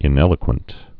(ĭn-ĕlə-kwənt)